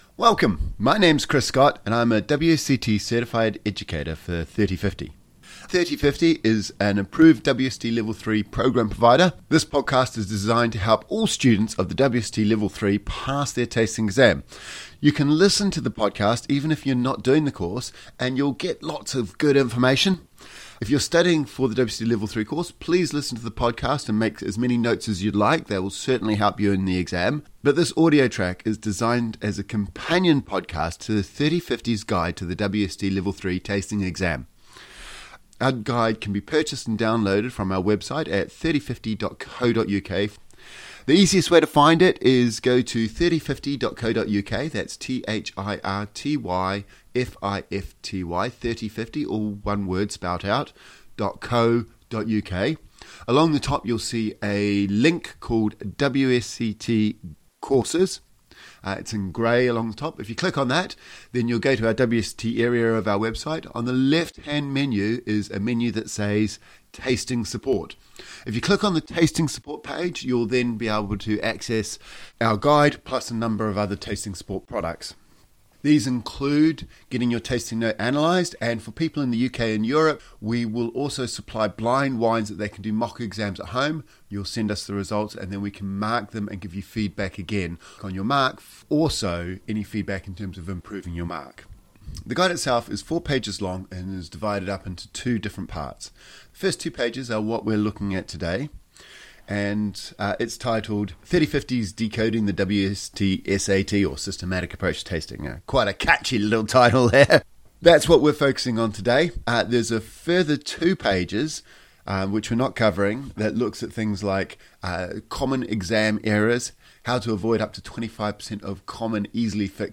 ThirtyFifty's audio guide to the WSET Level 3 Tasting. All listeners will gain valuable insights into tasting and preparing for the their wine tasting exam.